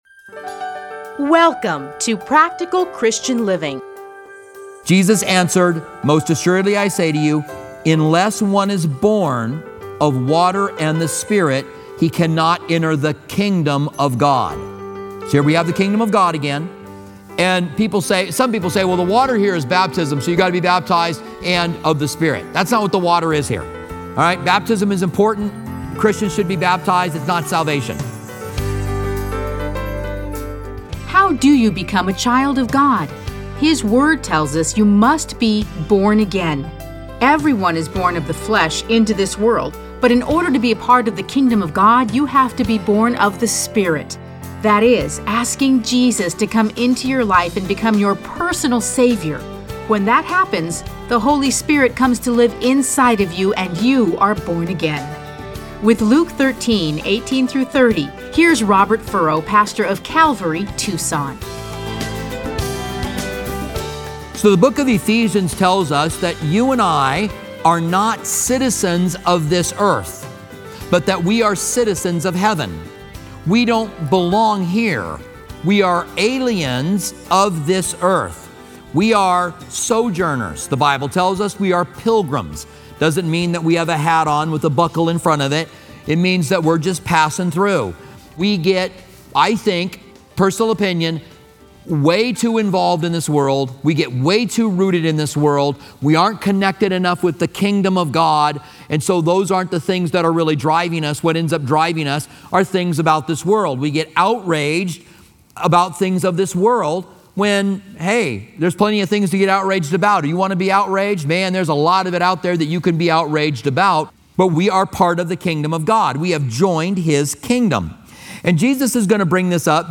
Listen to a teaching from Luke 13:18-30.